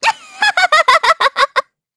Xerah-Vox_Happy1_kr_Madness.wav